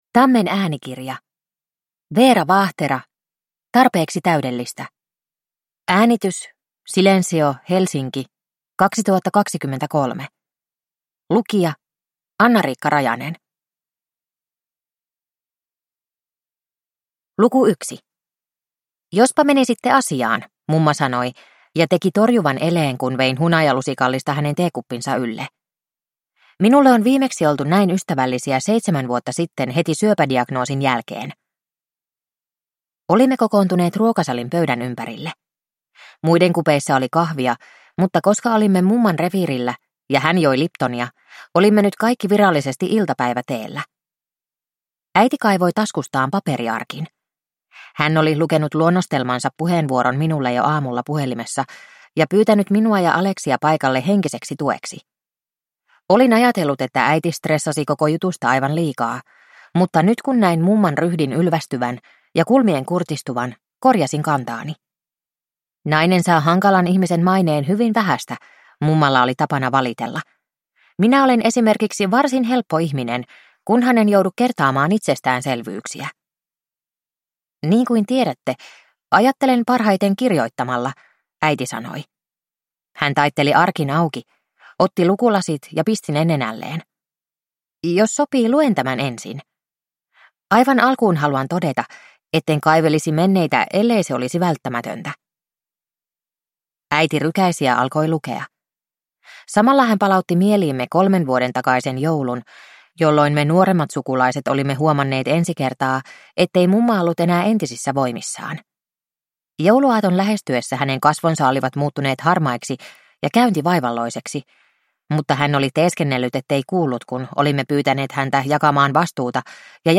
Tarpeeksi täydellistä (ljudbok) av Veera Vaahtera